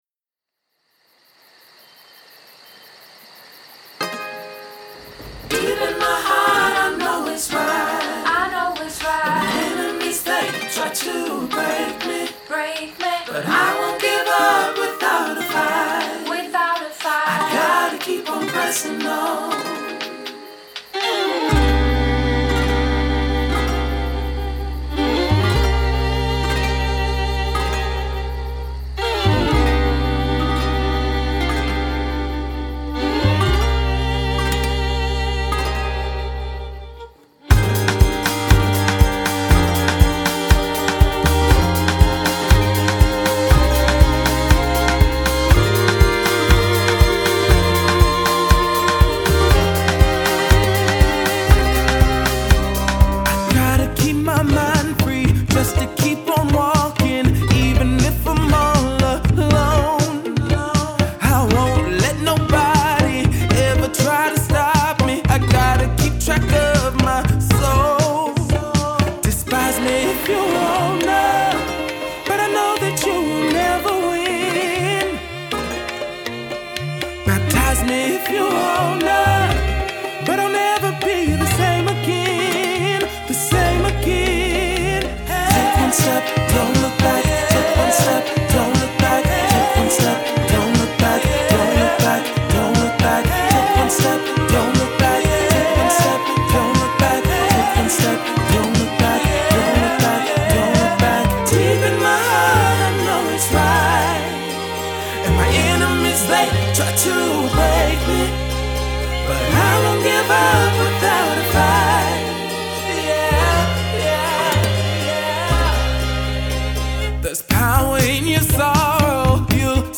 It’s still driven by violin